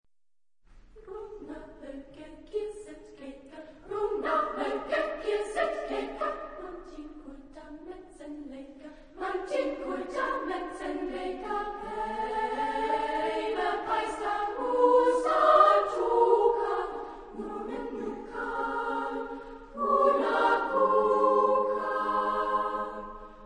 Género/Estilo/Forma: Canto coral ; Profano
Tipo de formación coral: SSAA  (4 voces Coro femenino )
Tonalidad : mixolidio